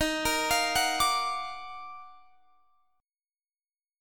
EbmM9 Chord
Listen to EbmM9 strummed